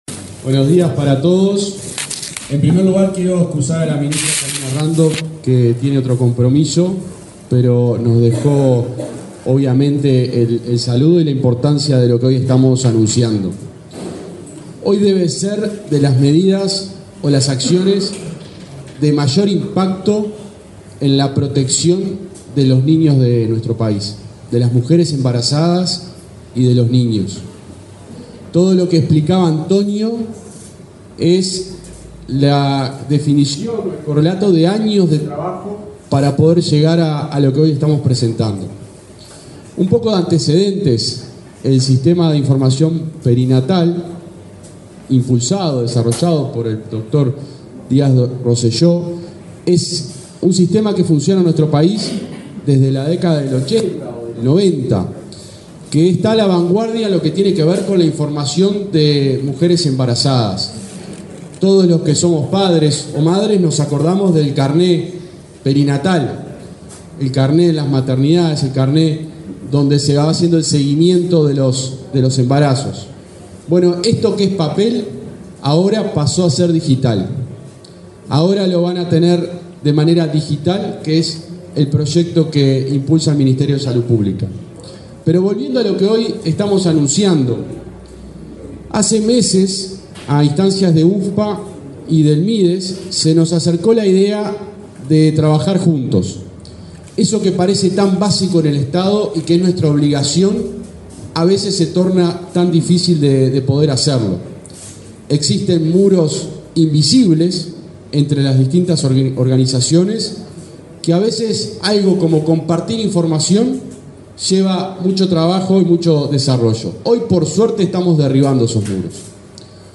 Palabra de autoridades en acto en el Mides
participaron, este lunes 18 en la sede del Ministerio de Desarrollo Social (Mides), en la presentación de avances del programa integral de primera infancia.